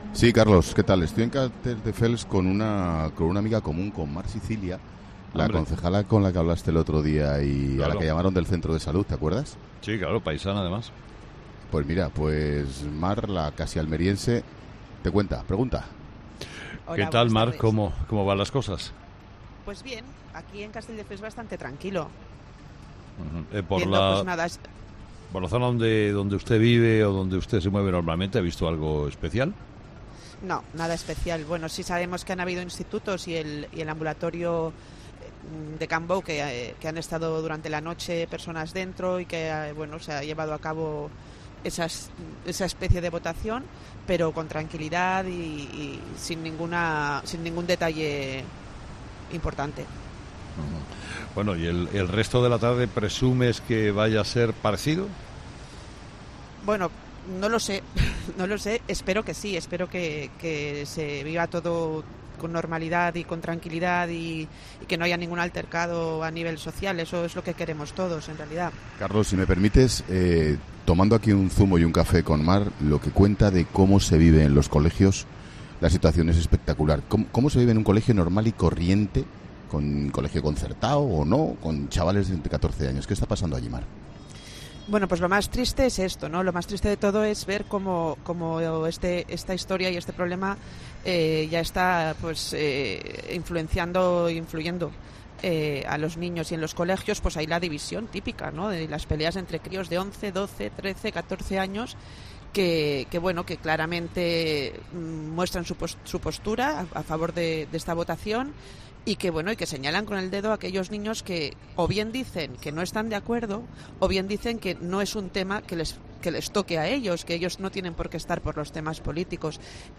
Mar Sicilia, concejal del PP en Castelldefels